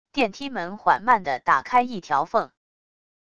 电梯门缓慢的打开一条缝wav音频